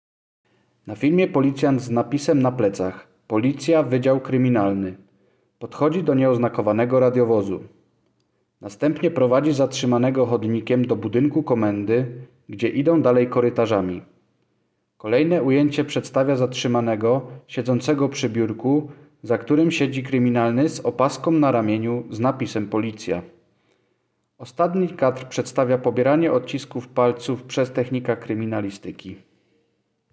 Nagranie audio audiodeskrypcja_narkotyki.m4a